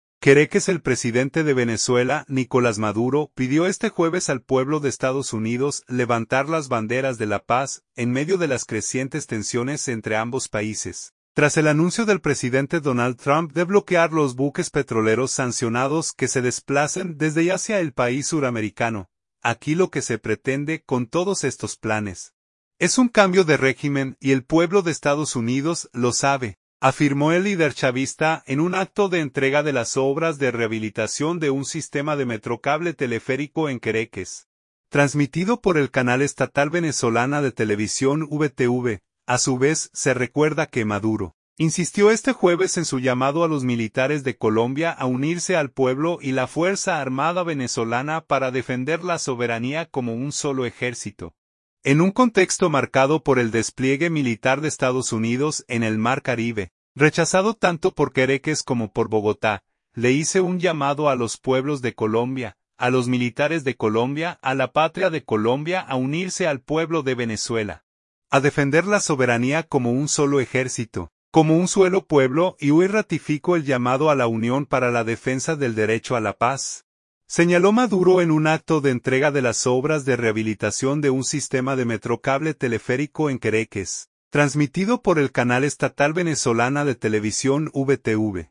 "Aquí lo que se pretende, con todos estos planes, es un cambio de régimen, y el pueblo de Estados Unidos lo sabe", afirmó el líder chavista, en un acto de entrega de las obras de rehabilitación de un sistema de metrocable (teleférico) en Caracas, transmitido por el canal estatal Venezolana de Televisión (VTV).